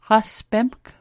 Pronunciation Guide: hgas·bemk